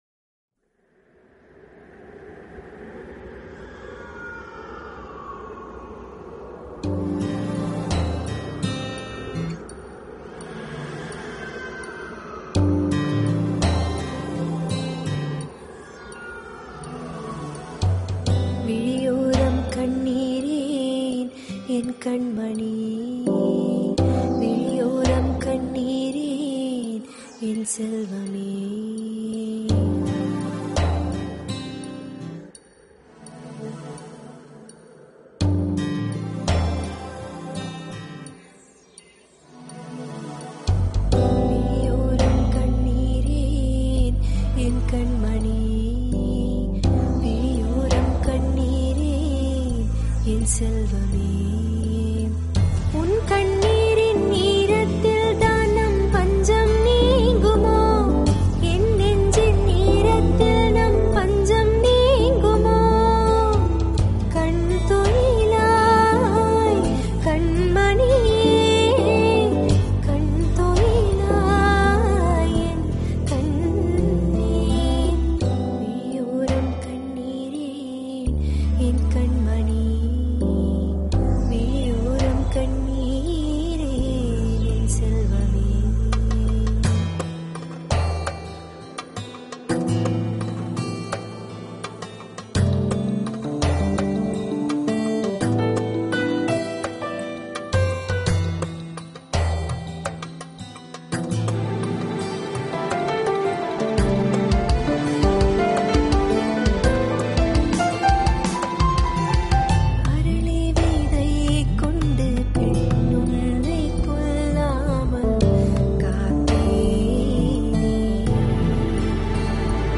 Dialup - LoFi Version: (